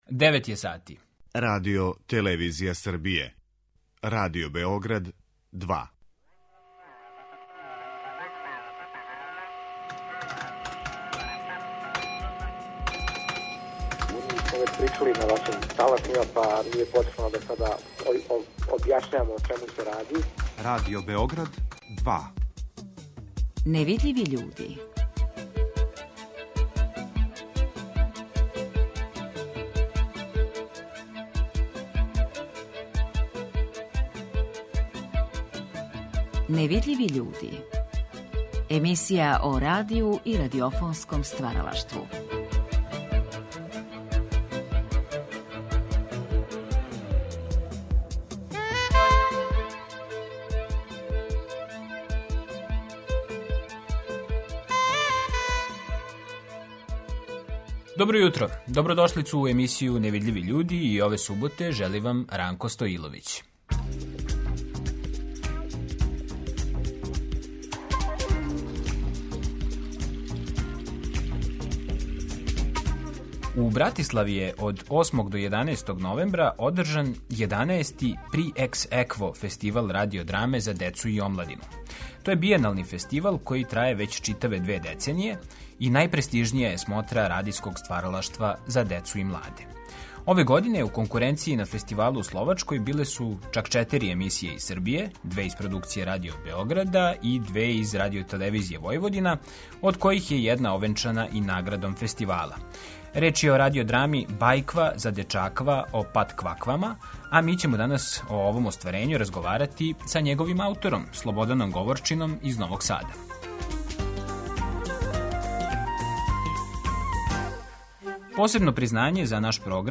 Емисија о радију и радиофонском стваралаштву.